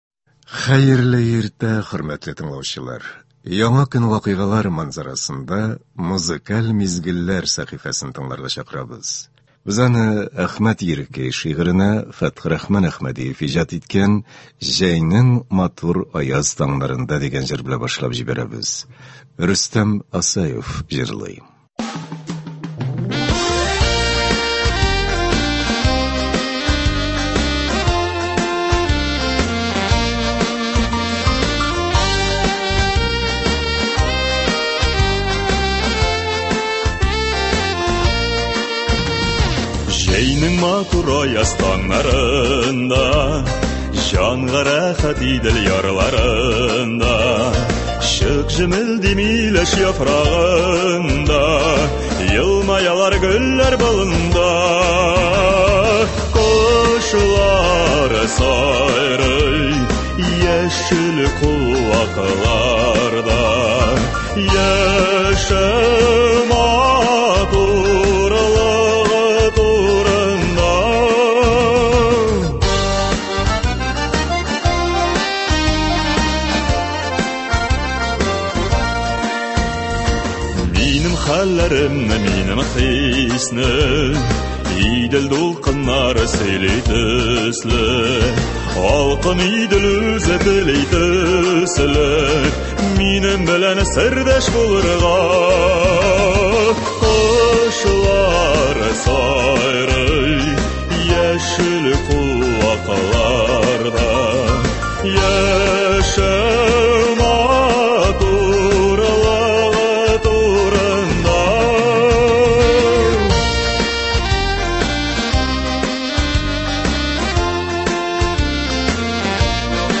Музыкаль мизгелләр – бу иртәдә безнең радио сезгә көн буена яхшы кәеф бирә торган җырлар тәкъдим итә.